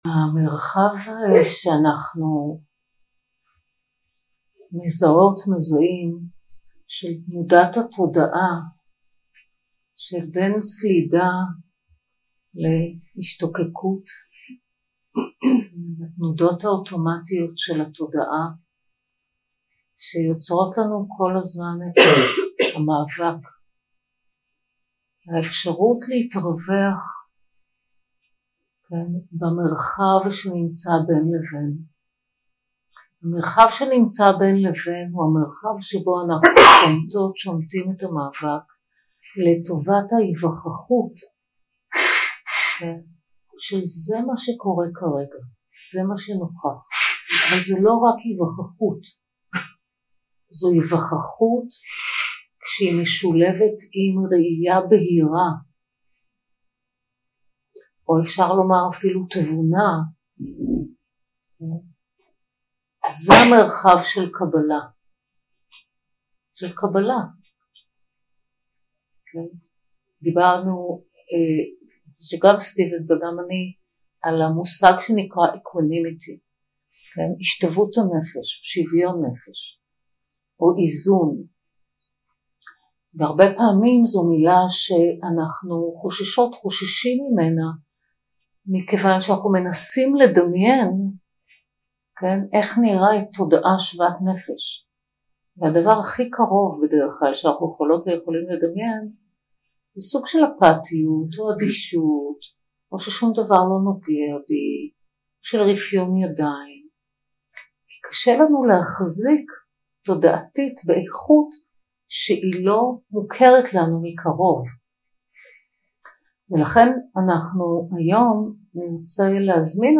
יום 5 – הקלטה 13 – בוקר – מדיטציה מונחית – בין הפסקת המאבק לקבלה | Tovana
יום 5 – הקלטה 13 – בוקר – מדיטציה מונחית – בין הפסקת המאבק לקבלה 0:00 0:00 סוג ההקלטה: Dharma type: Guided meditation שפת ההקלטה: Dharma talk language: Hebrew